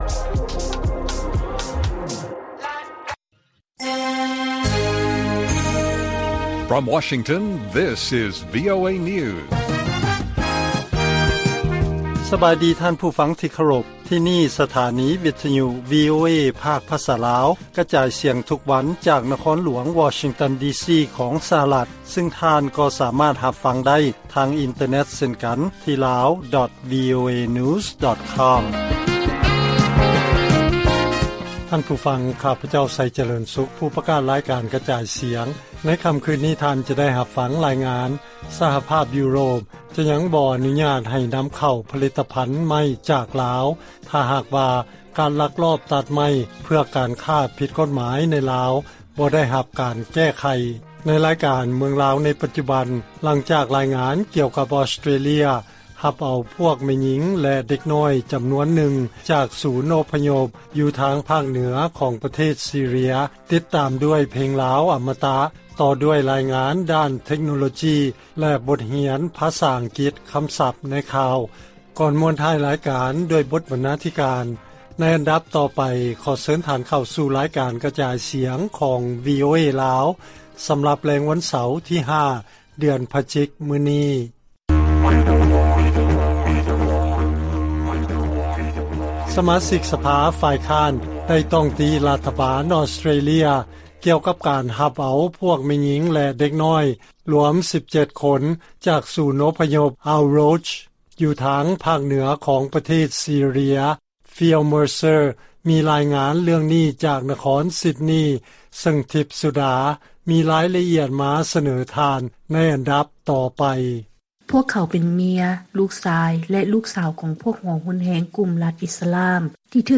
ລາຍການກະຈາຍສຽງຂອງວີໂອເອລາວ: ສະຫະລັດຫັນໄປຫາກຳປັ່ນບໍ່ມີຄົນຂັບ ສຳລັບເຂດອິນໂດປາຊີຟິກ